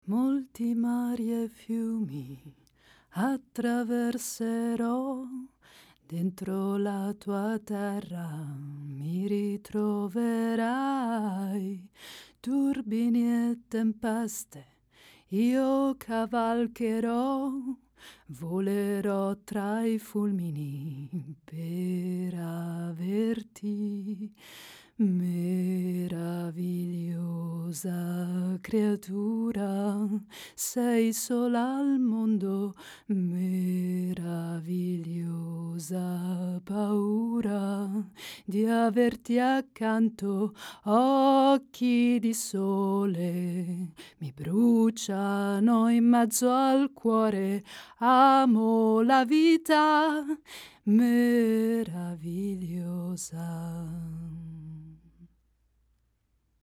a multilingual voice actress with a warm, versatile, and expressive vocal presence
ITALIAN - SINGING